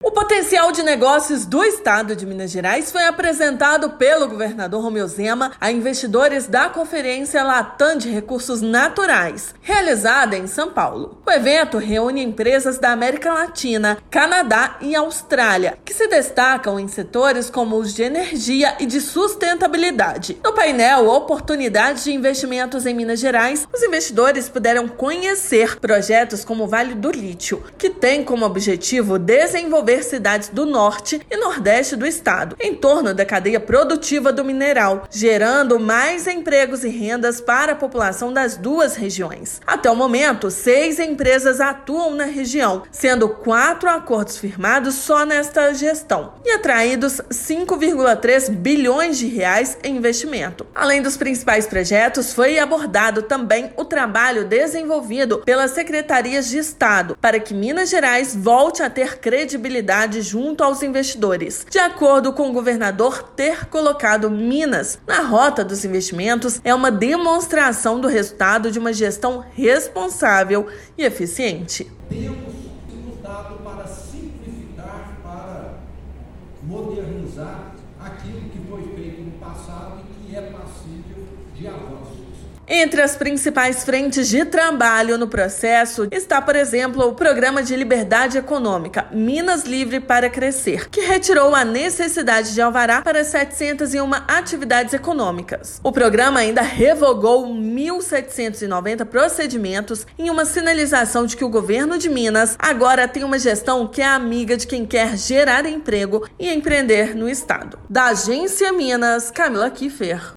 Durante o evento Latam Natural Resources Conference, em São Paulo, empresas da América Latina, Canadá e Austrália conheceram as cadeias produtivas do lítio, nióbio, fosfato, potássio e silício. Ouça matéria de rádio.